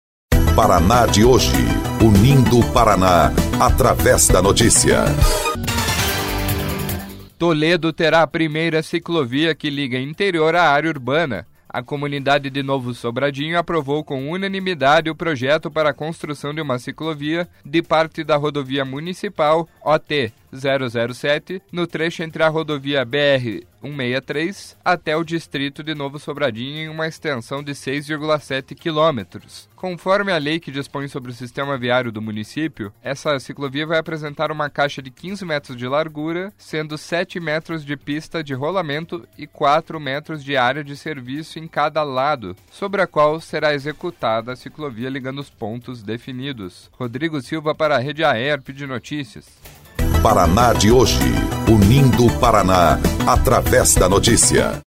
11.06 – BOLETIM – Primeira ciclovia que liga interior a área urbana será em Toledo